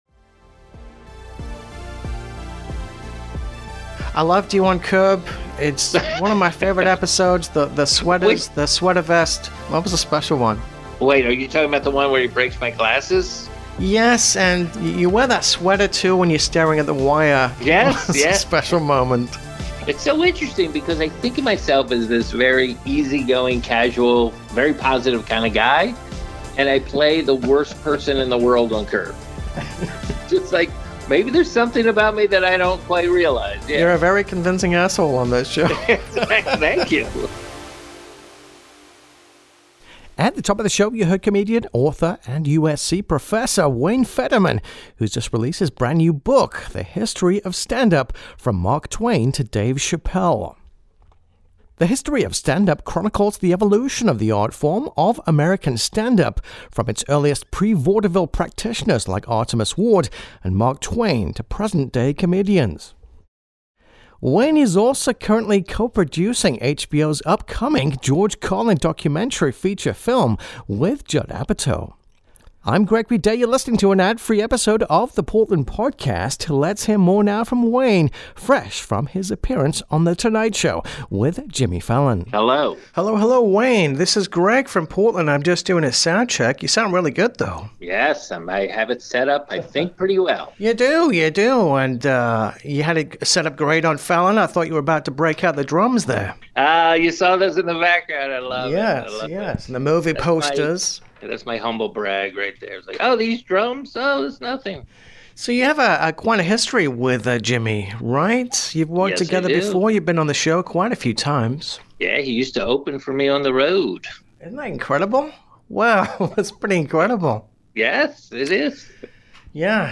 Today we chat with comedian, author & USC professor Wayne Federman whose just released his new book 'The History of Stand-Up: From Mark Twain to Dave Chappelle' which chronicles the evolution of the art form of American standup from its earliest pre-vaudeville practitioners like Artemus Ward and Mark Twain to present-day comics. Wayne also is currently co-producing HBO's upcoming George Carlin documentary with Judd Apatow.